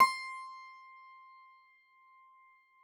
53j-pno18-C4.wav